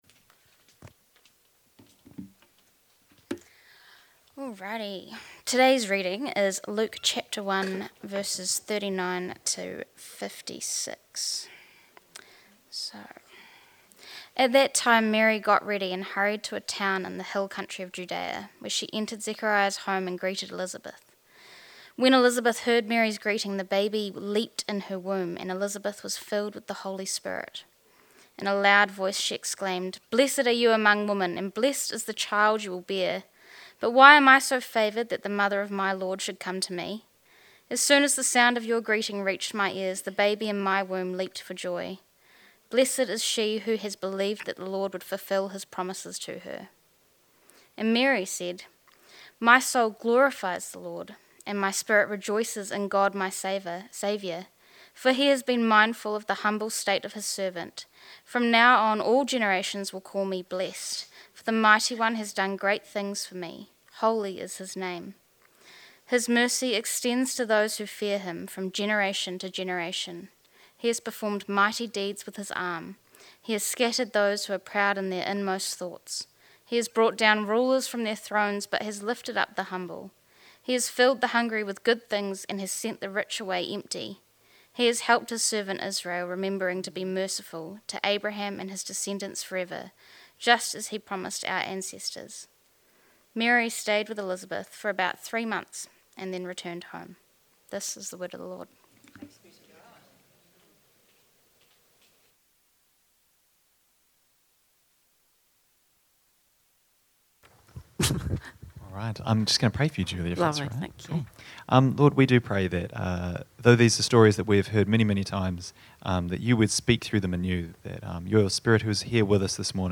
Sermons | All Saints Parish Palmerston North